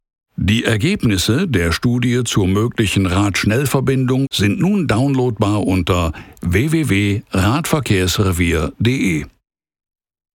Experienced (30+ years) versatile German voice actor
Corporate - Radverkehrsrevier (Excerpt) – GERMAN
I record from my fully equipped studio, delivering high-quality audio with a fast turnaround–depending on project scope and availability–to help you communicate your message effectively and connect authentically with your audience.